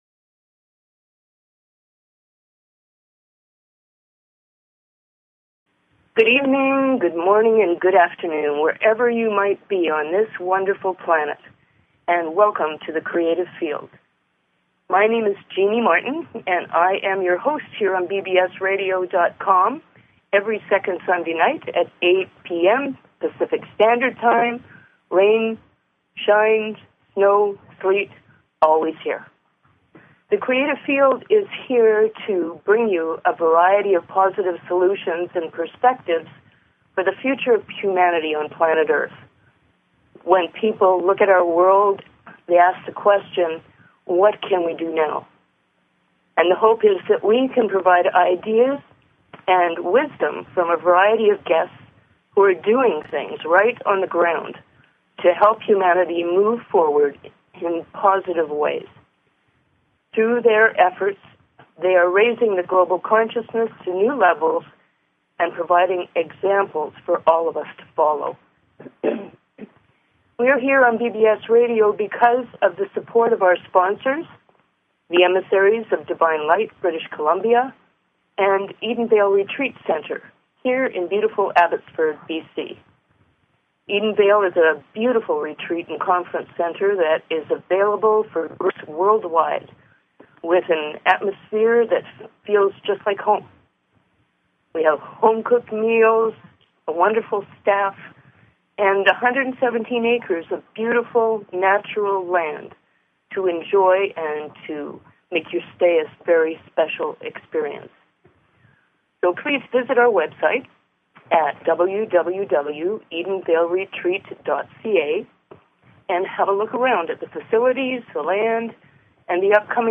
Talk Show Episode, Audio Podcast, The_Creative_Field and Courtesy of BBS Radio on , show guests , about , categorized as